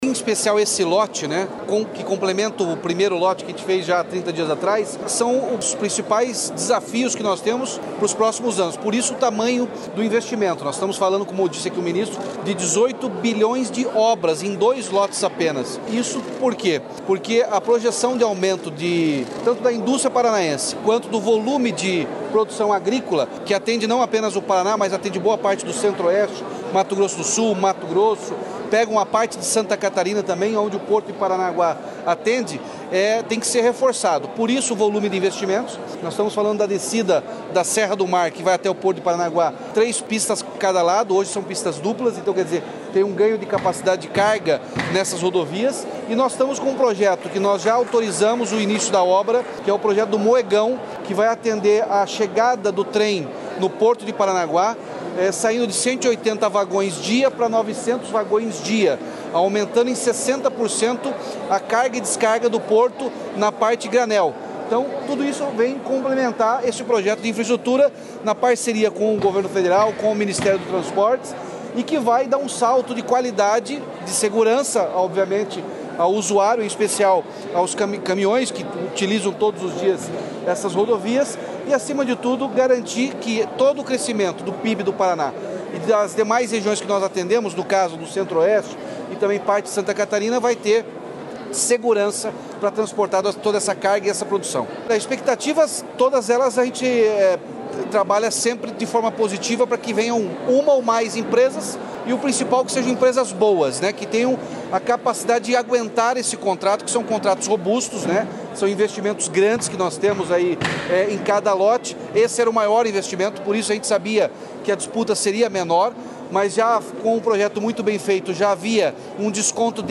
Sonora do governador Ratinho Junior sobre a realização do leilão do Lote 2 das novas concessões rodoviárias do Paraná
RATINHO JUNIOR - LEILAO LOTE 2 COLETIVA.mp3